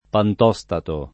[ pant 0S tato ]